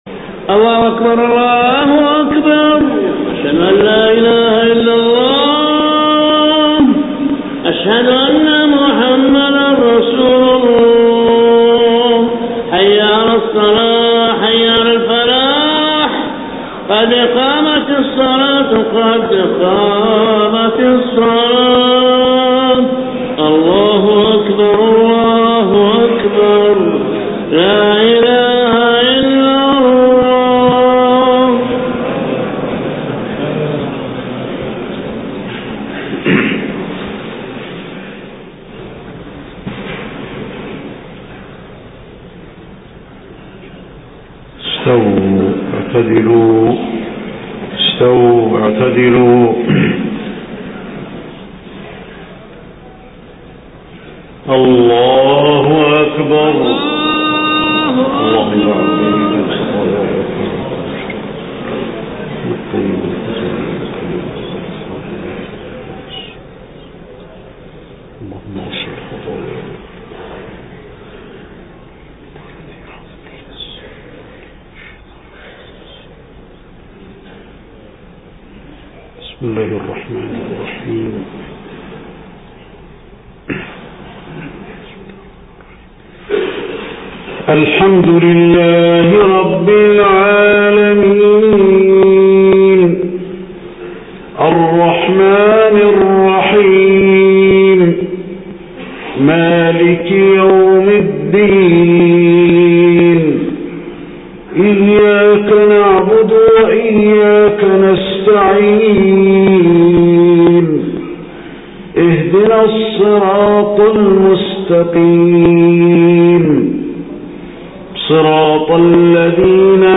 صلاة المغرب 25 محرم 1430هـ من سورة الاحزاب 40-48 > 1430 🕌 > الفروض - تلاوات الحرمين